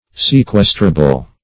Search Result for " sequestrable" : The Collaborative International Dictionary of English v.0.48: Sequestrable \Se*ques"tra*ble\, a. Capable of being sequestered; subject or liable to sequestration.
sequestrable.mp3